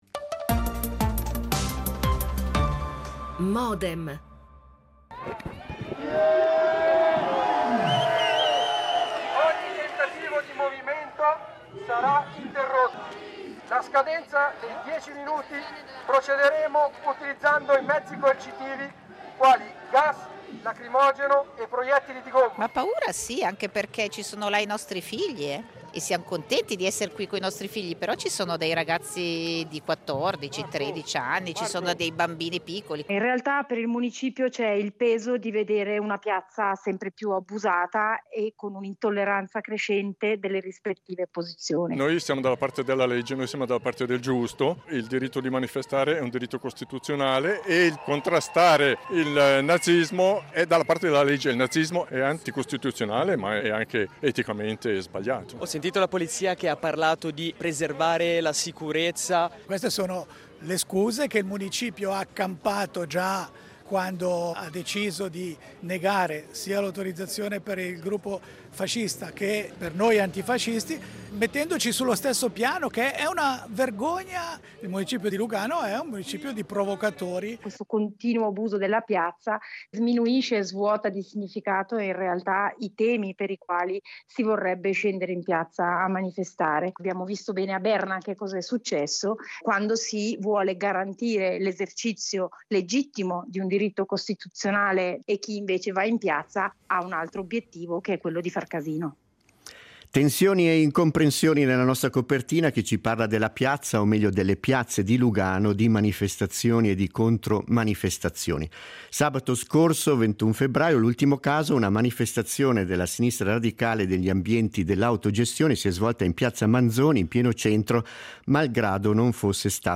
Un dibattito sull’uso (o abuso) delle piazze cittadine a cui abbiamo invitato:
L'attualità approfondita, in diretta, tutte le mattine, da lunedì a venerdì